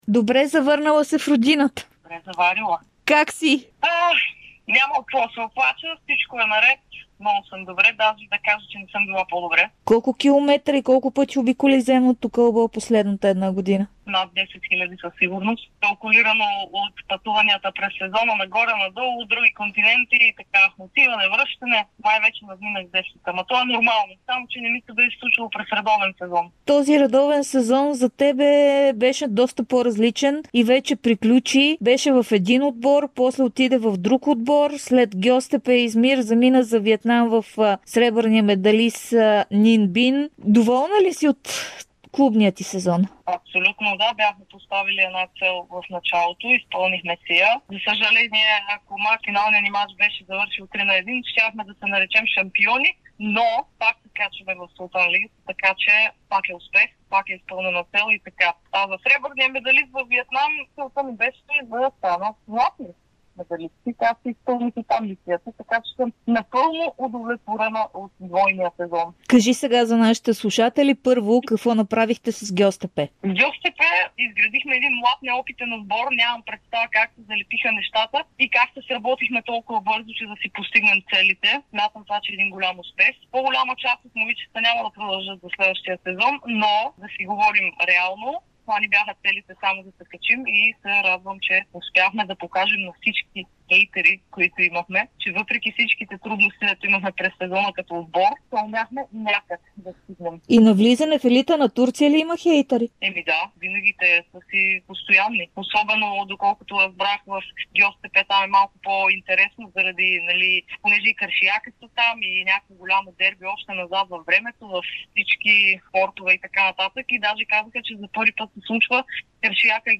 Последният капитан на женския ни национален отбор по волейбол Мирослава Паскова ексклузивно пред Дарик и dsport сподели как спечели промоция в елита на Турция с Гьозтепе Измир, отношението към Станимир Стоилов там, изживяното във Виетнам, където спечели всичките си мачове с Нин Бин, но пропусна награждаването, предстоящата утре операция на глезена и отсъствието от националния отбор това лято.